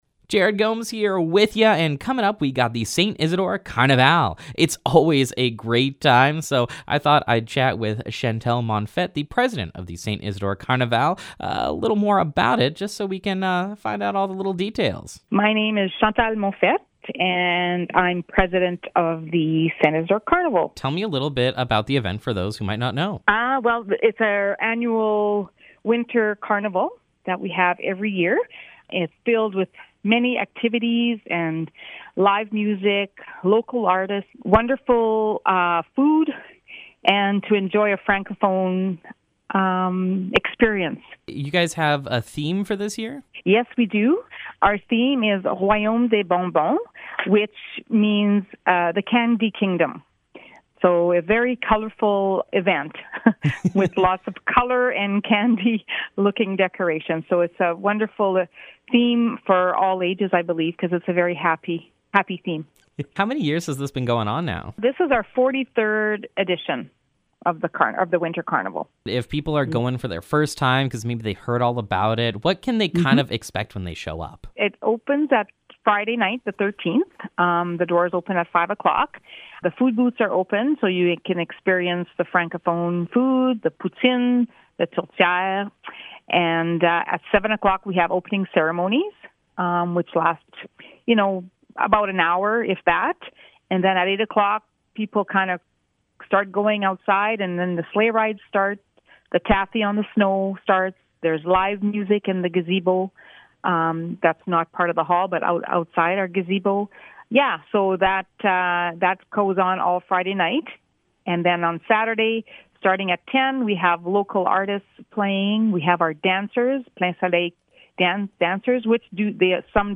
St. Isidore Carnaval Interview
st-isadore-carnaval-interview-2026-final.mp3